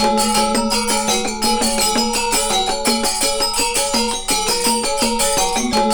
GAMELAN 1.wav